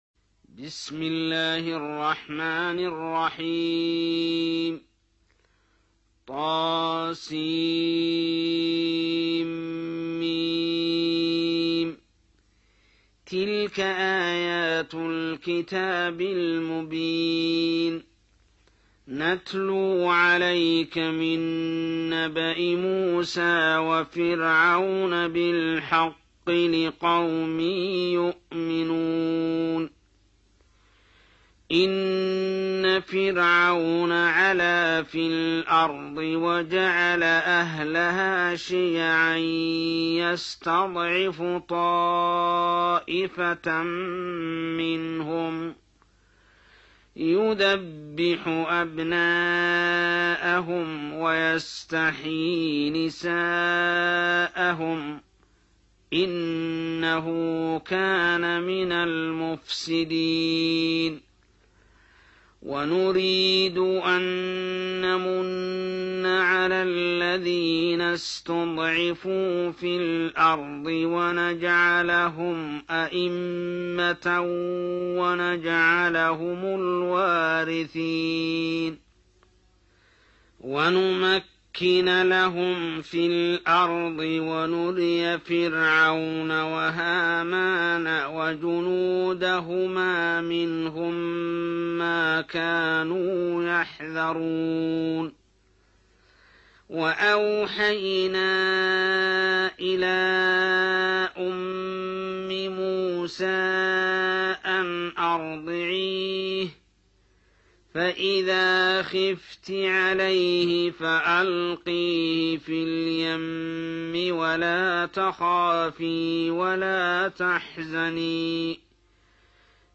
28. Surah Al-Qasas سورة القصص Audio Quran Tarteel Recitation
حفص عن عاصم Hafs for Assem